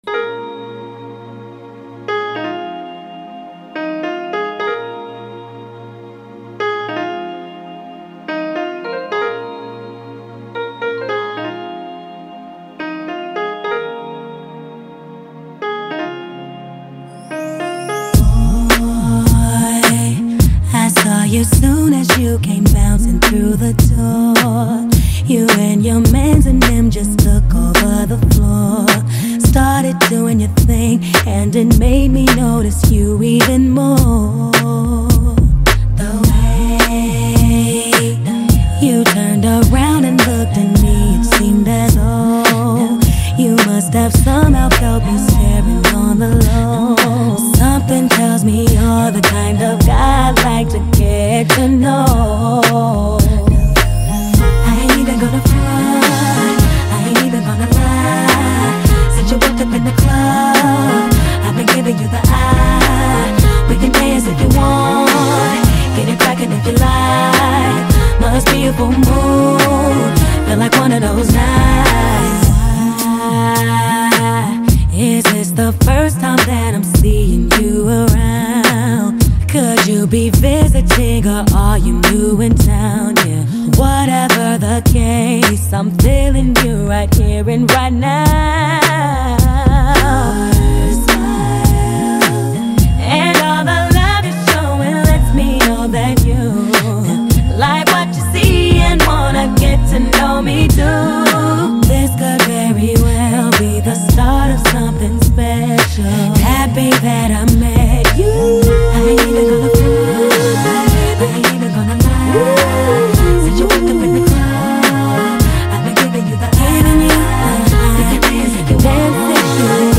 heartwarming